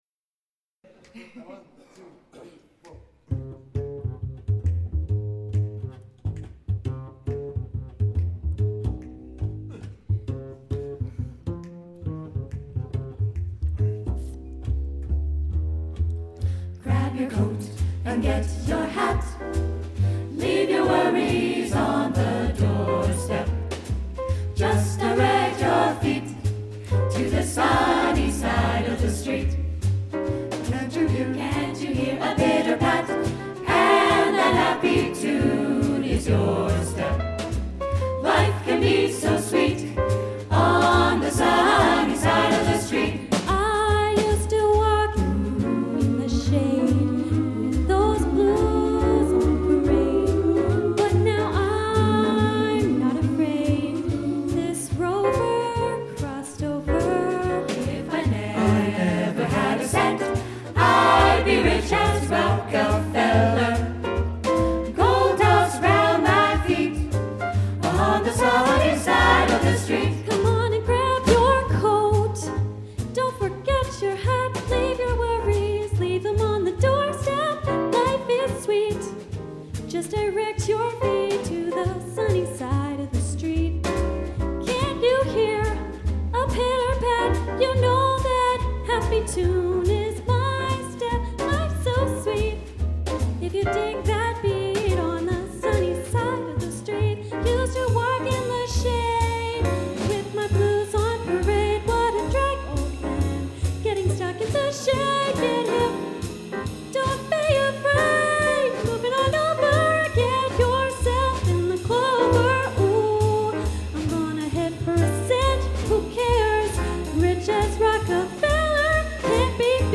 S A T B /rhythm section